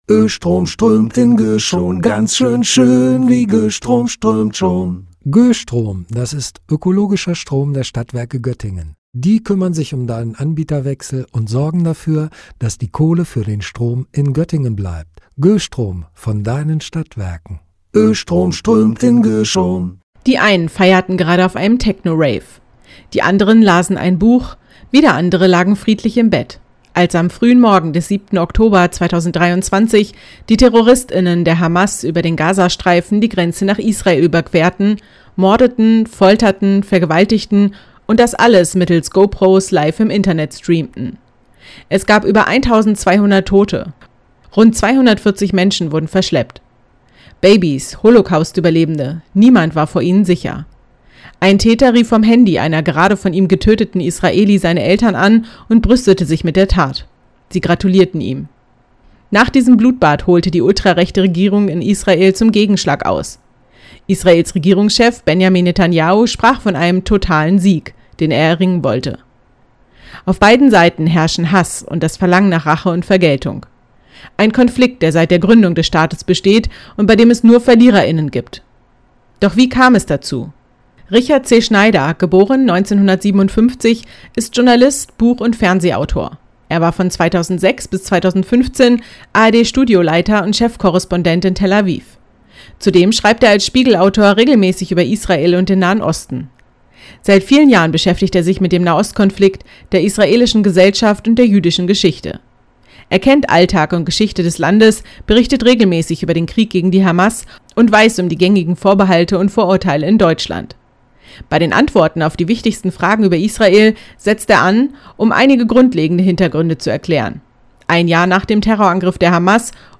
Beiträge > Rezension: "Die Sache mit Israel" von Richard C. Schneider - StadtRadio Göttingen